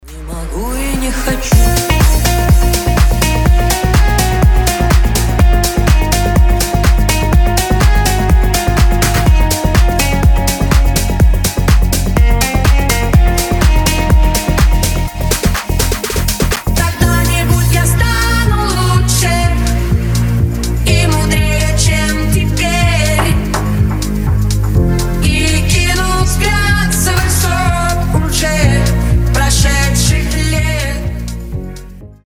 Club House
Mashup
ремиксы